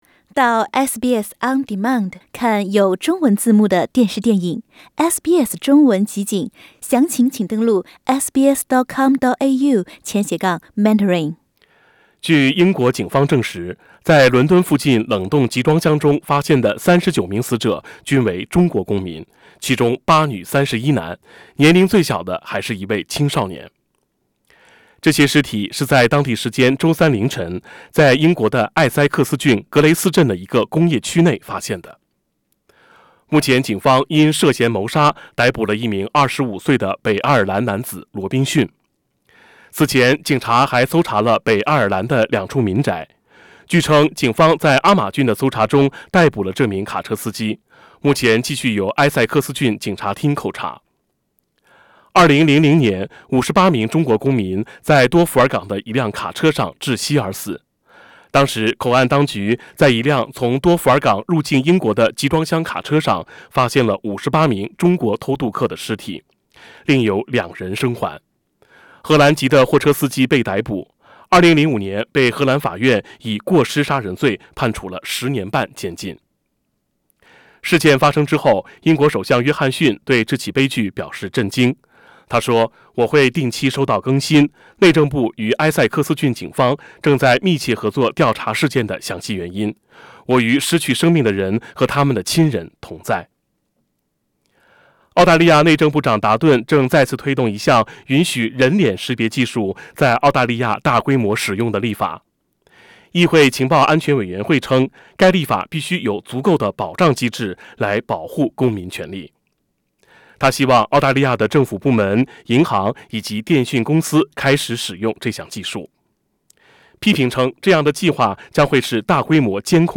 SBS Chinese Evening News Oct 25